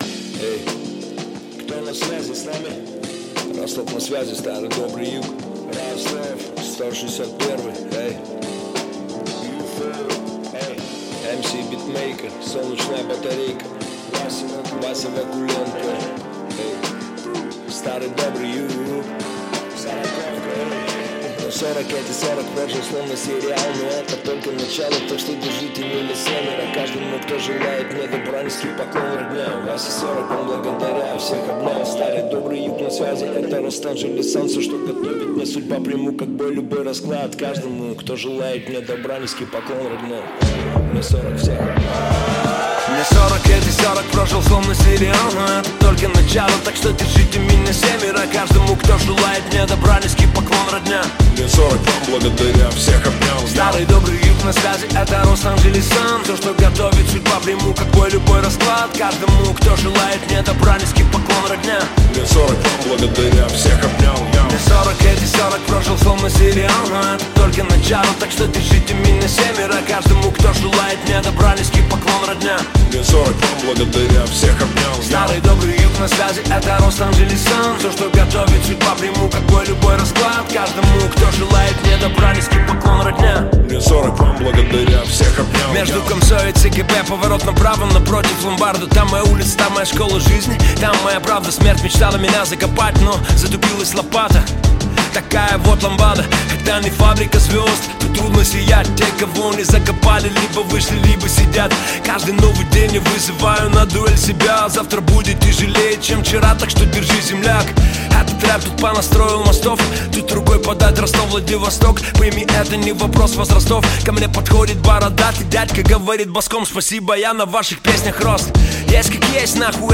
Хип-хоп
Жанр: Жанры / Хип-хоп